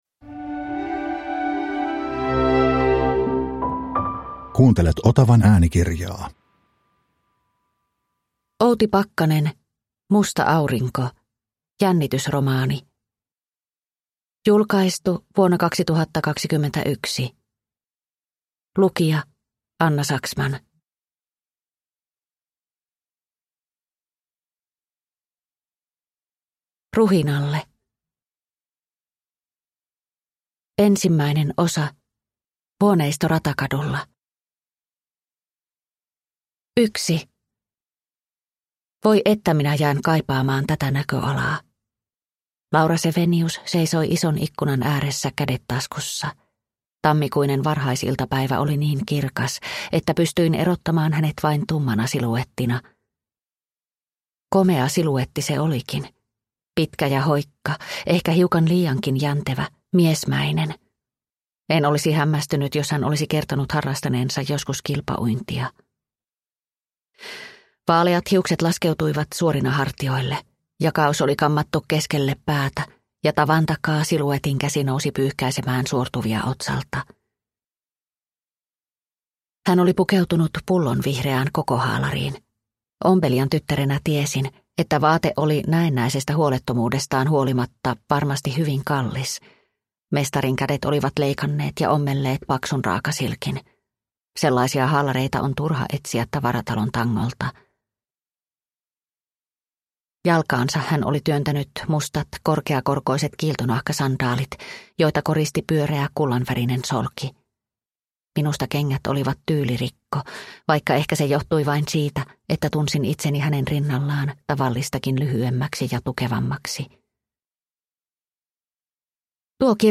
Musta aurinko – Ljudbok – Laddas ner